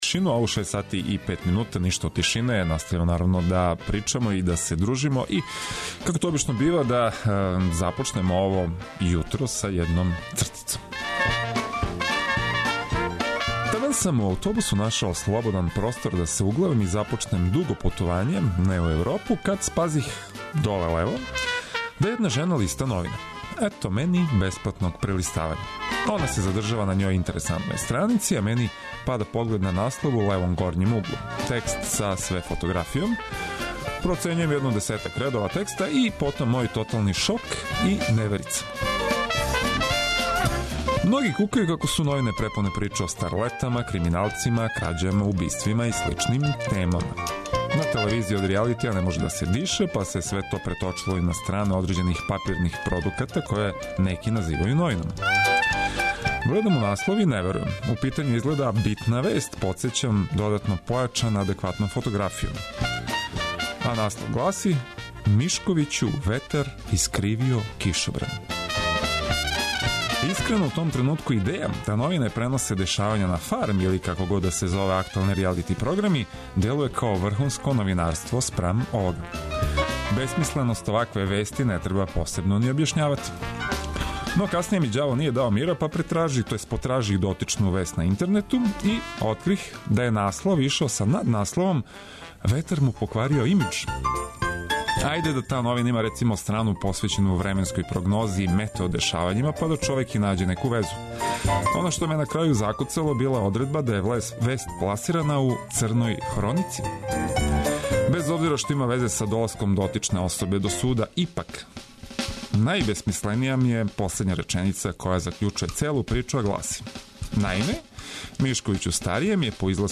Било да се тешко будите, или лако скочите на ноге из кревета, Устанак је ту да вас наоружа осмехом и најновијим информацијама за успешан почетак новог дана. Одлична музика је неопходни бонус!